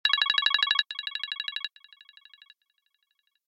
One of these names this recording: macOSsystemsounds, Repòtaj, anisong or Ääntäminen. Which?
macOSsystemsounds